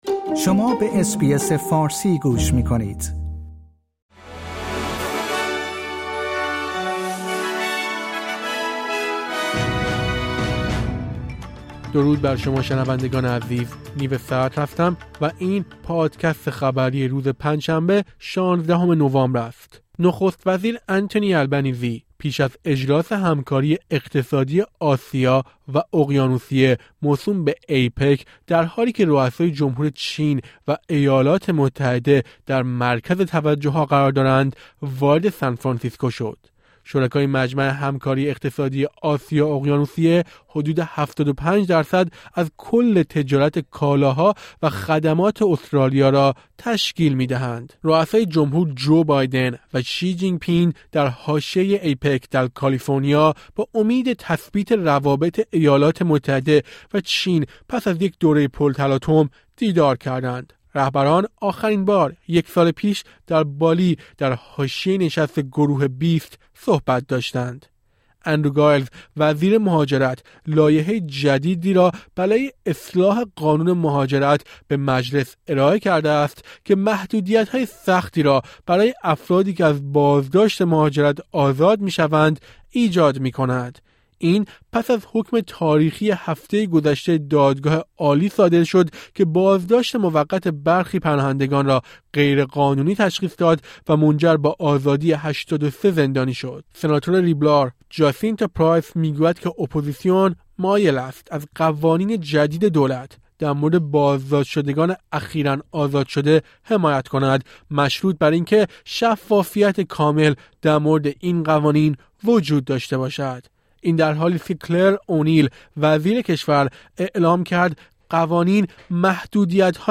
در این پادکست خبری مهمترین اخبار استرالیا و جهان در روز پنجشنبه ۱۶ نوامبر ۲۰۲۳ ارائه شده است.